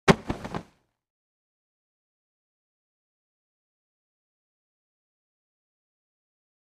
Cloth Whoosh And Grab